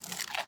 sounds / mob / panda / eat2.ogg
eat2.ogg